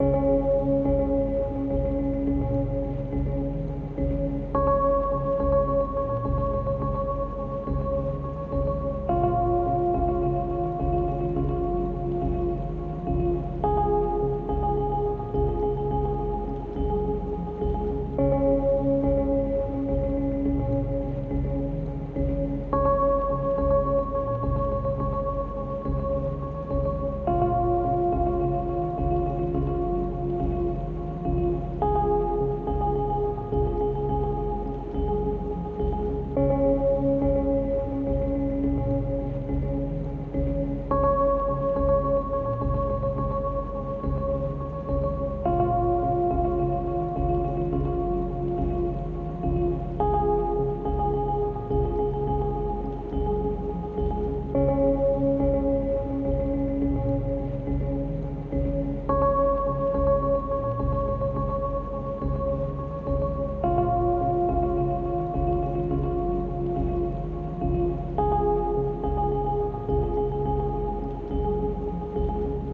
Type BGM
Speed 60%